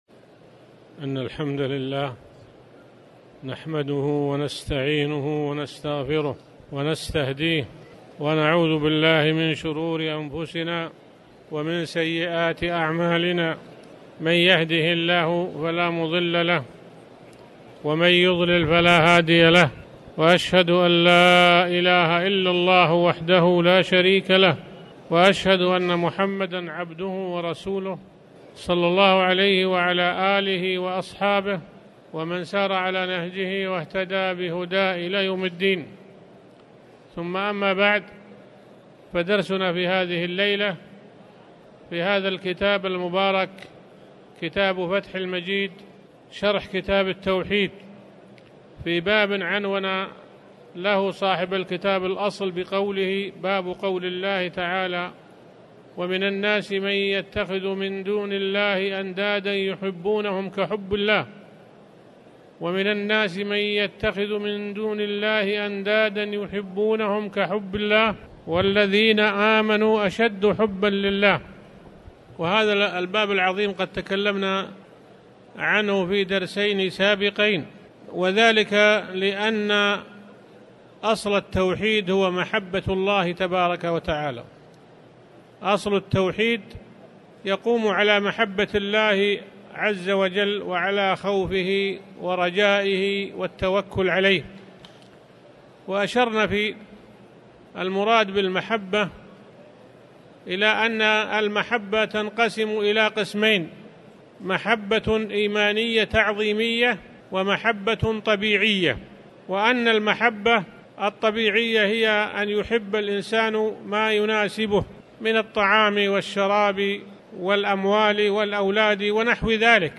تاريخ النشر ٩ ذو القعدة ١٤٣٨ هـ المكان: المسجد الحرام الشيخ